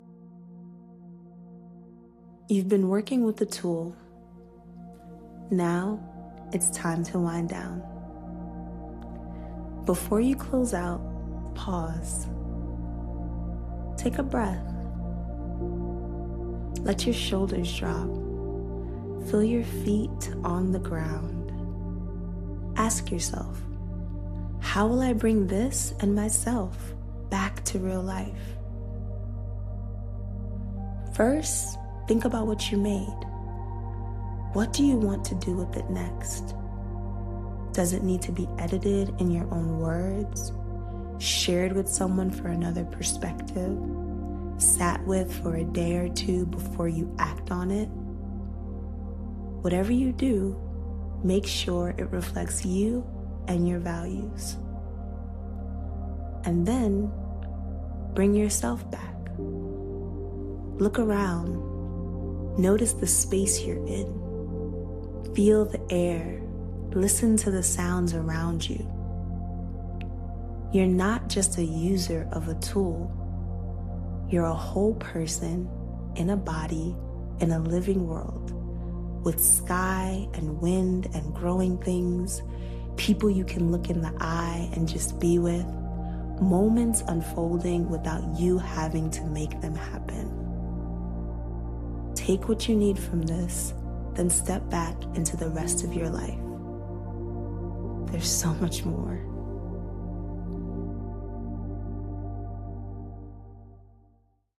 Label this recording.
Guided Practice: